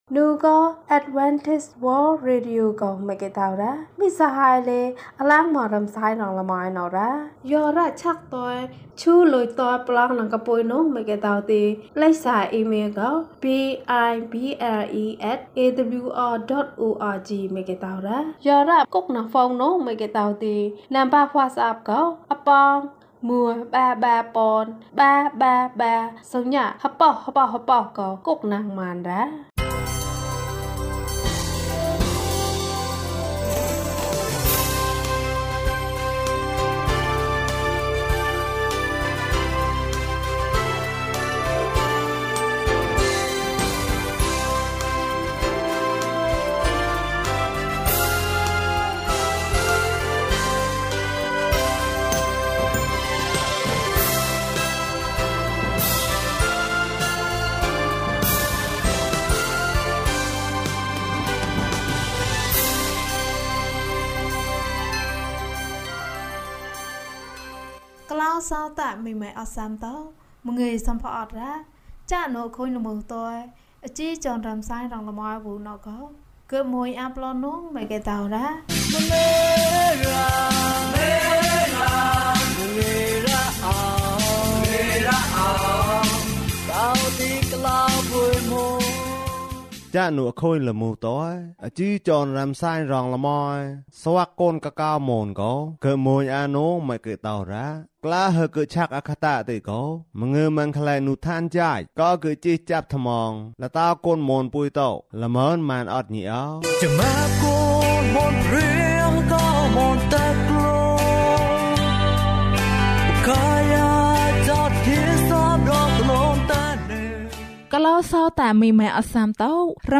သင်၏ဘုရားသခင်အား ချီးမွမ်းကြလော့။ ကျန်းမာခြင်းအကြောင်းအရာ။ ဓမ္မသီချင်း။ တရားဒေသနာ။